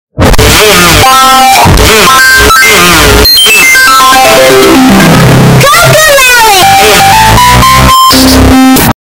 Brain Damage (loud Asf)